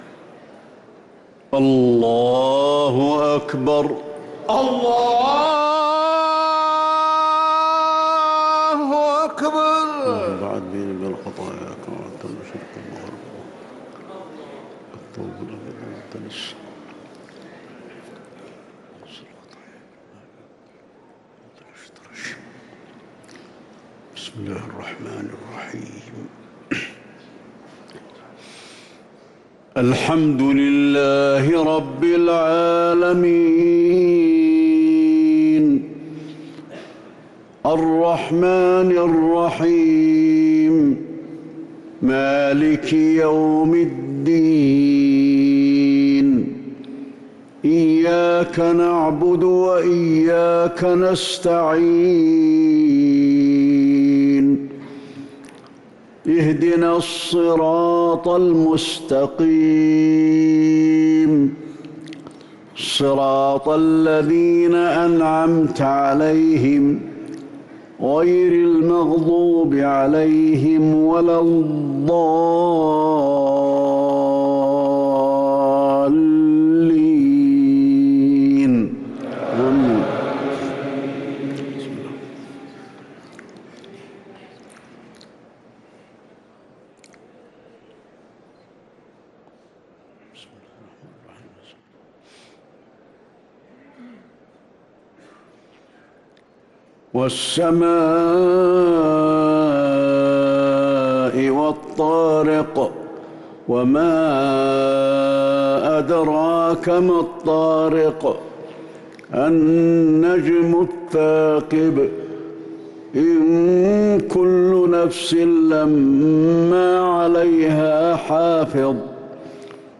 صلاة المغرب للقارئ علي الحذيفي 27 رمضان 1444 هـ
تِلَاوَات الْحَرَمَيْن .